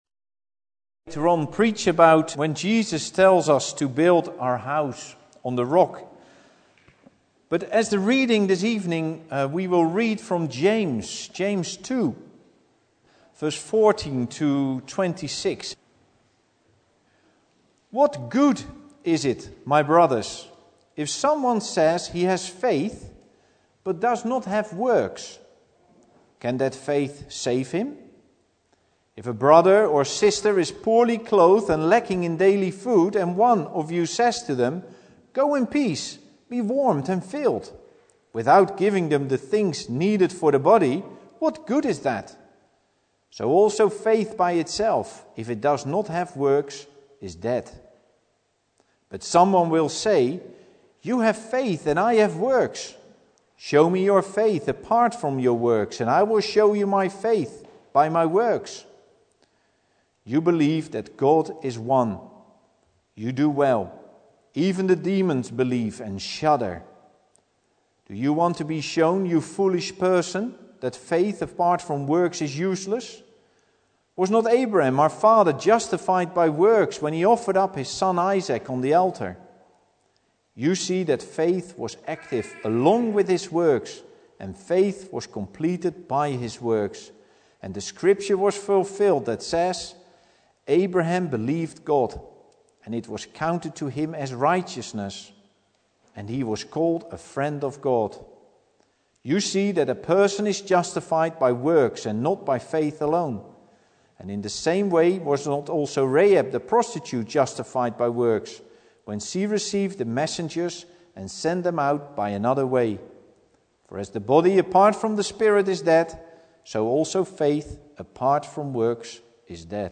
Matthew 7:24-27 Service Type: Sunday Evening Bible Text